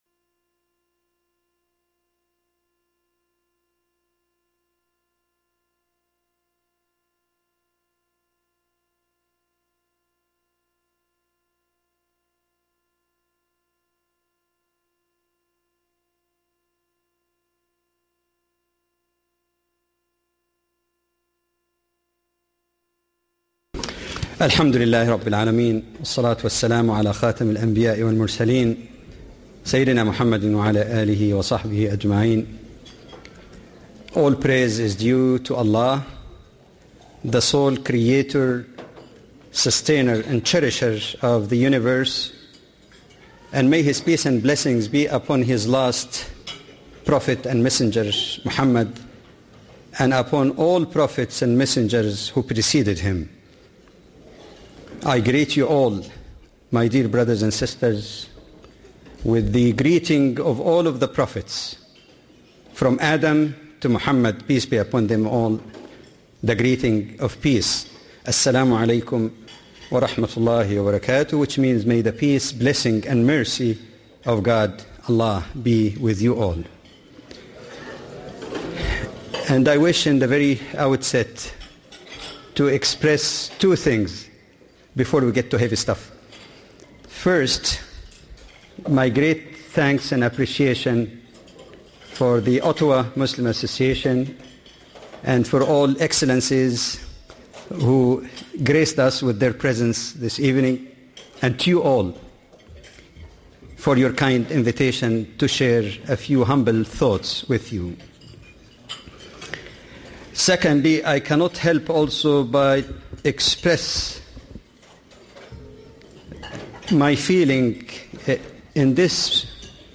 Muslim/Non-Muslim Relations: Commonly Misunderstood Qur'anic Texts - A lecture by Dr. Jamal Badawi.